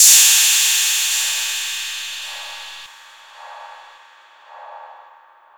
RIDEFX1   -L.wav